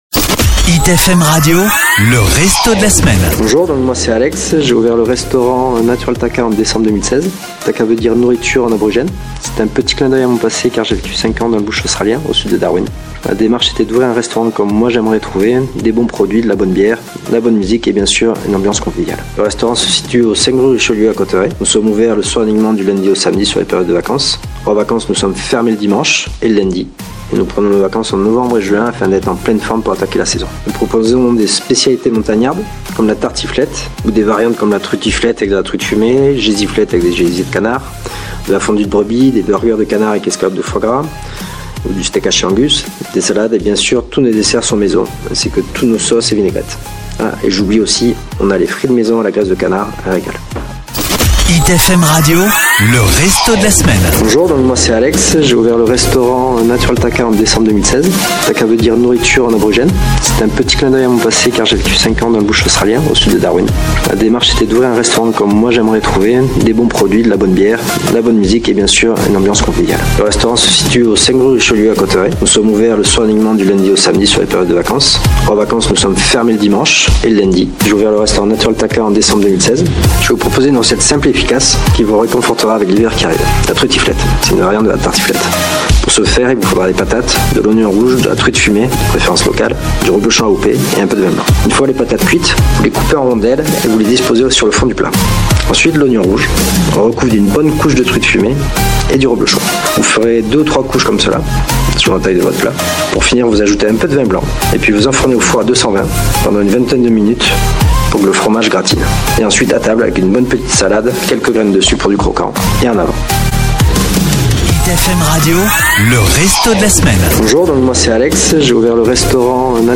Nous vous proposons de retrouver l'interview complète du restaurant de la semaine grâce à ce podcast